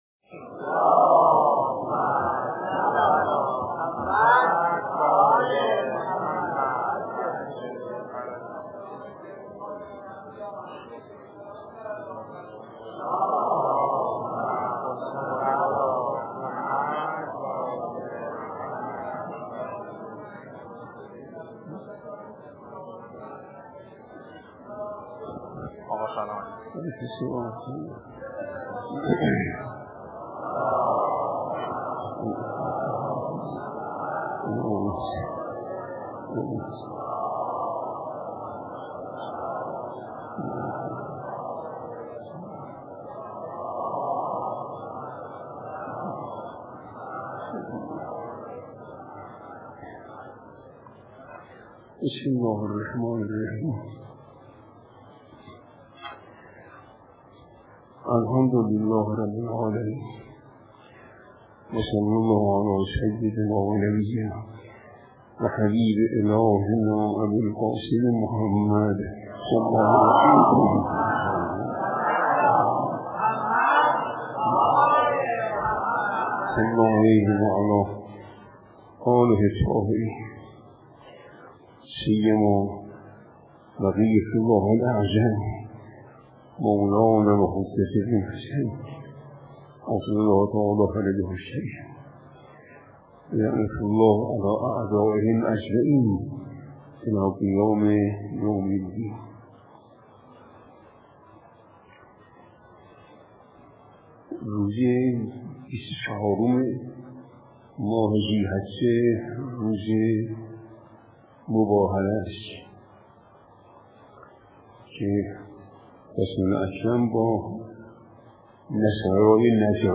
گروه اندیشه: مفسر قرآن کریم در جلسه تفسیر این هفته به بیان اجمالی از ماجرای مباهله پرداخت و گفت: آیه مباهله مفسر همه آیات قرآن است.